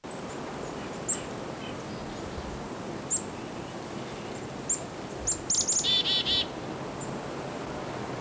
Fig.2. Pennsylvania November 24, 2001 (MO).
Black-capped Chickadee diurnal flight calls